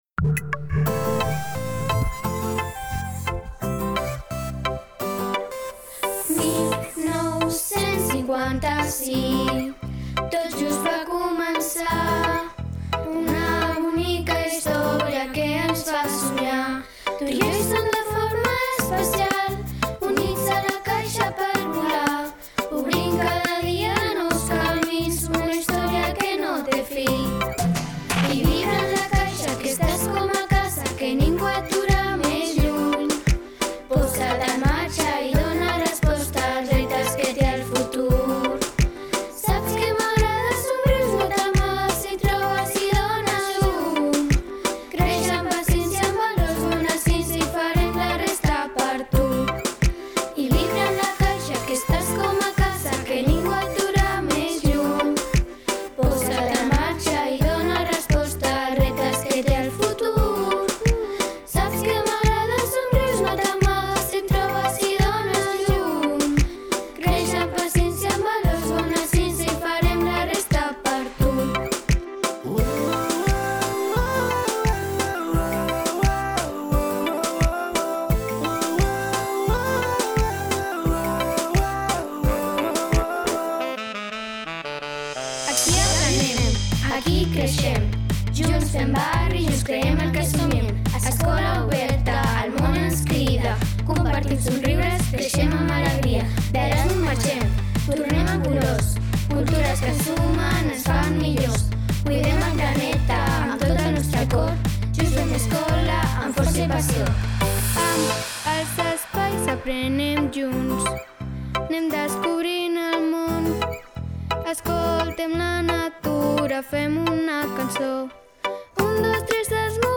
Escolteu-lo i gaudiu de la veu del nostre alumnat!